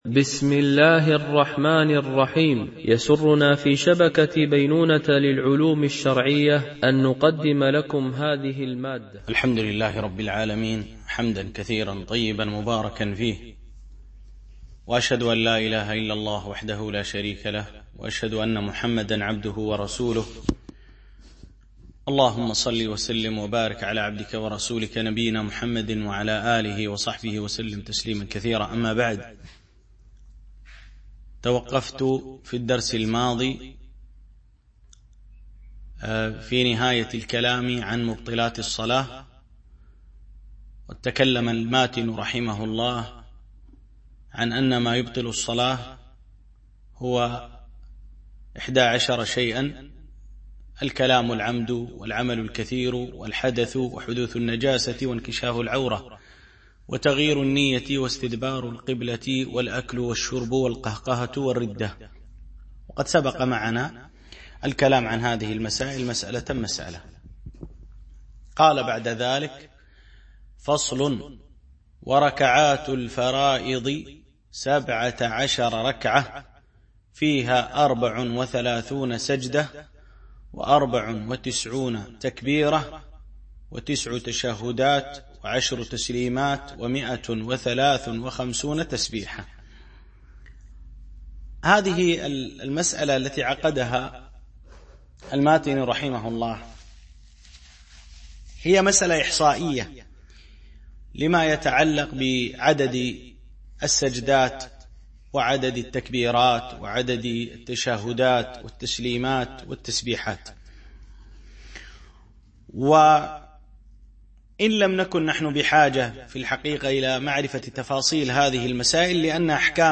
شرح متن أبي شجاع في الفقه الشافعي ـ الدرس 13